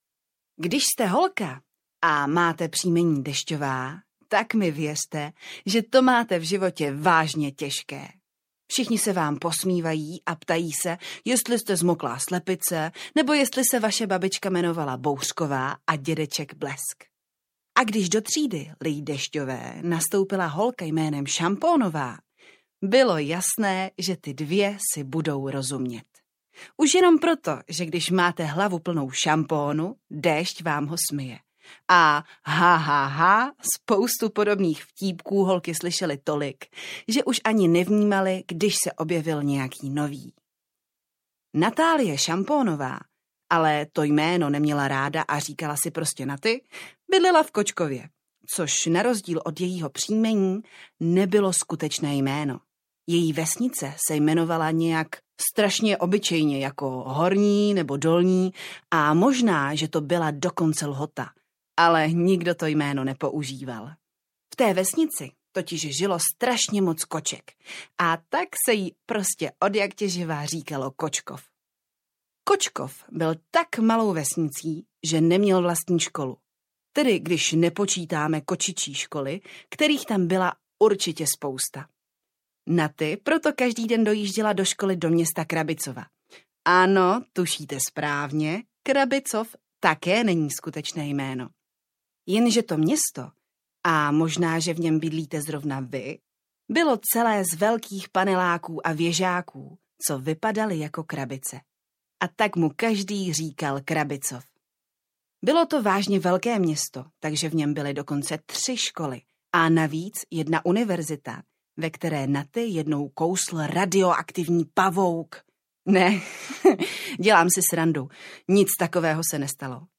Superholky audiokniha
Ukázka z knihy